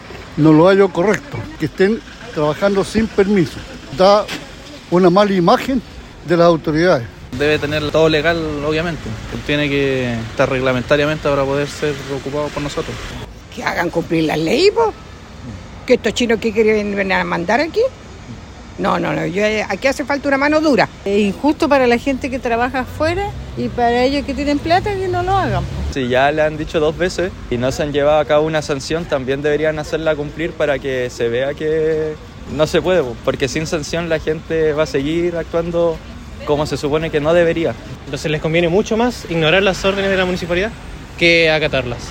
Algunos vecinos entrevistados por Radio Bío Bío señalaron incluso que se sienten vulnerados, al ver cómo un privado desoye las órdenes de la autoridad local, por lo que piden “mano dura”.
mall-chino-valdivia-vecinos.mp3